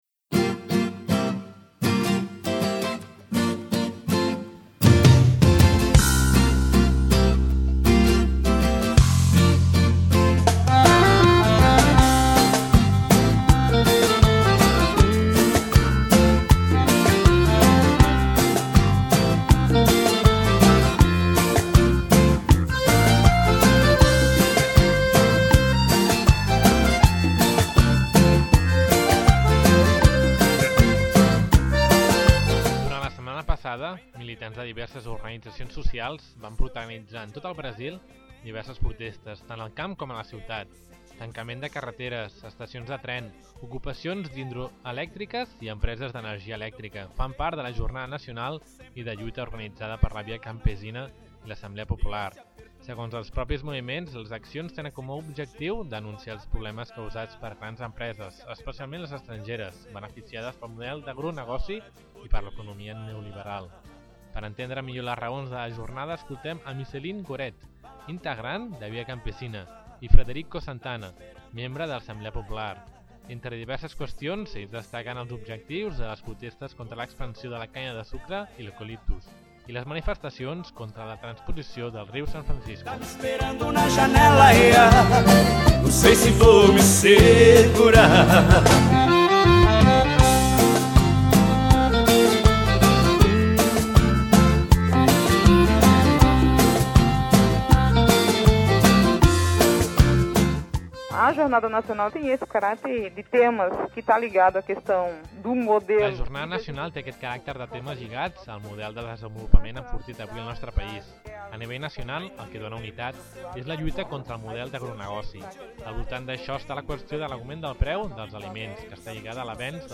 19/06/2008 - REPORTAJE: JORNADA NACIONAL de LUCHA en BRASIL
REPORTAJE sobre la Jornada de movilizaciones en Brasil